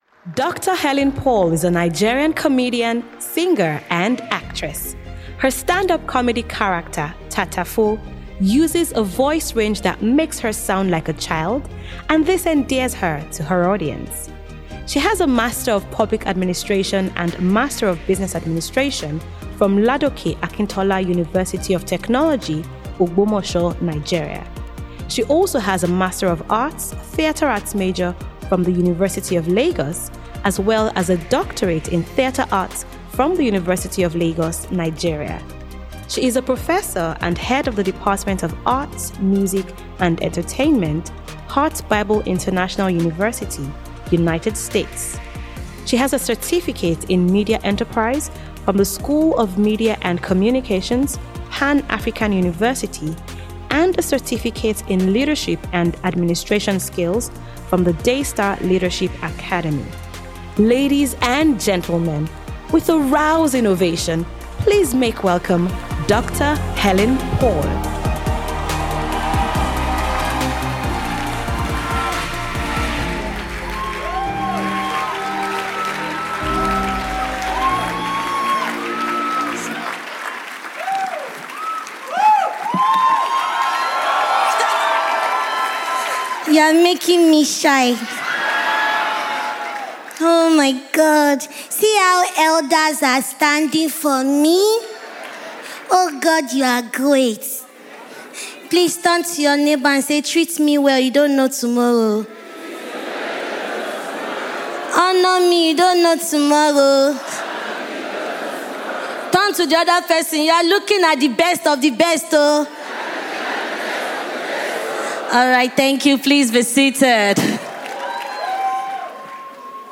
The 45-year-old film star is characterized by a voice range that makes her sound like a little baby .
Recounting her life experience, Helen Paul revealed in a summit, Excellence in Leadership Conference (ELC), which held in November 2nd & 3rd.